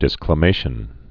(dĭsklə-māshən)